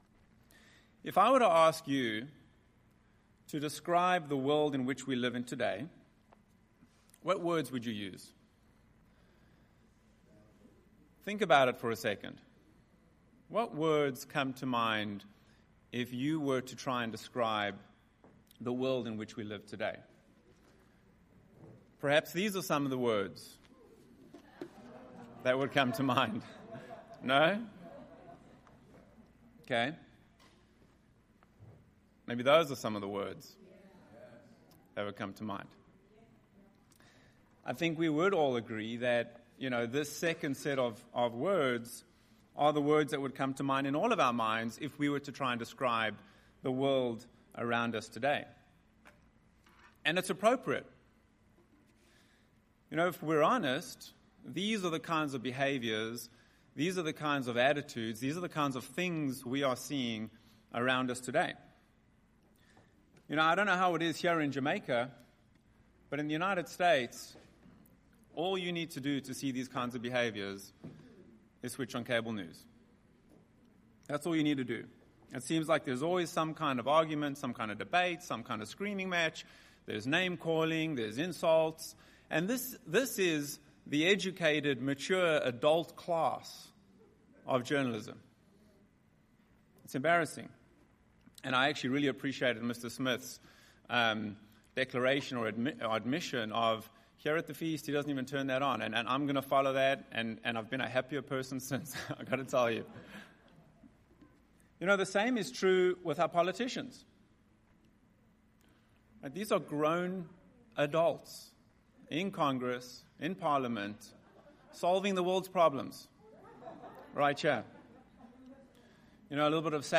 This sermon was given at the Montego Bay, Jamaica 2020 Feast site.